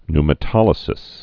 (nmə-tŏlĭ-sĭs, ny-)